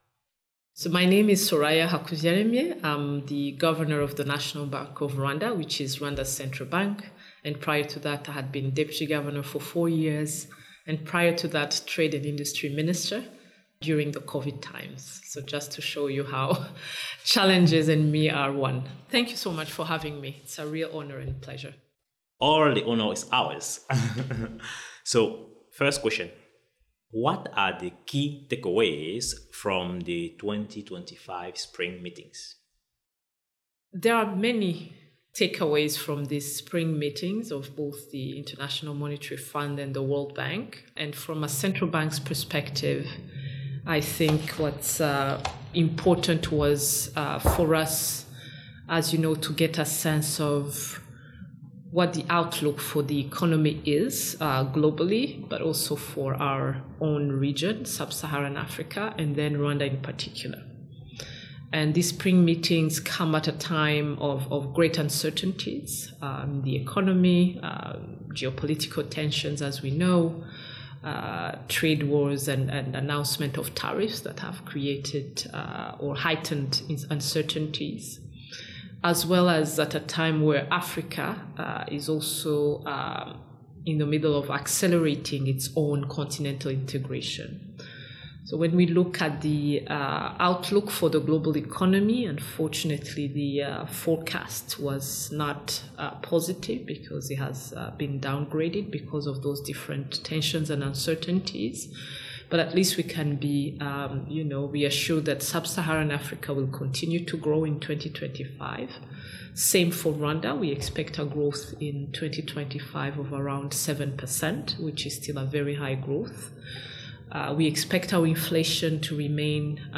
Foresight Africa podcast at the 2025 World Bank/IMF Spring Meetings
This audio recording is part of the World Bank/IMF Spring Meetings 2025 special episode of the Foresight Africa podcast.
In this interview, he speaks with Soraya Hakuziyaremye, governor of the National Bank of Rwanda.